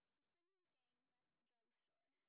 sp18_street_snr10.wav